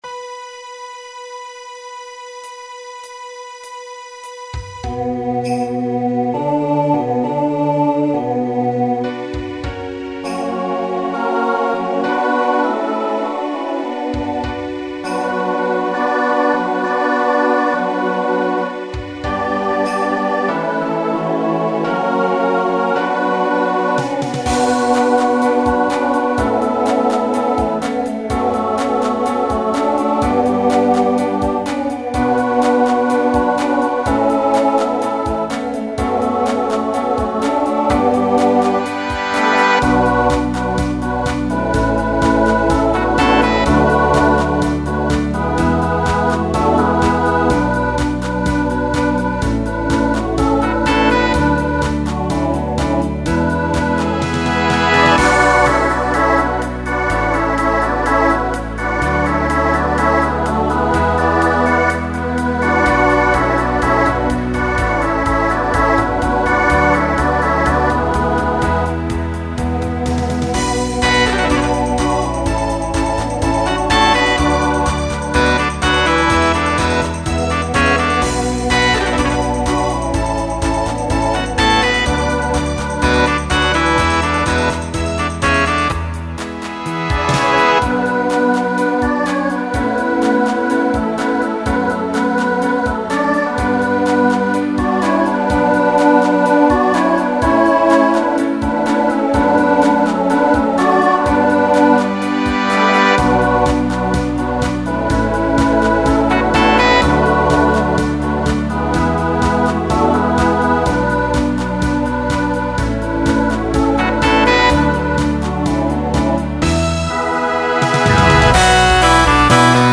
Genre Rock
Opener Voicing SATB